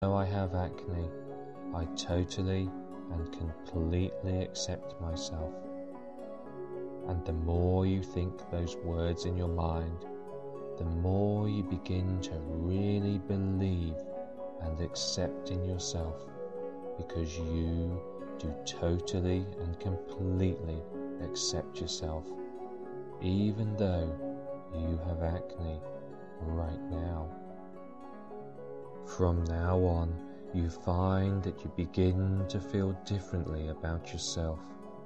Skin Cleansing Vocal Hypnosis MP3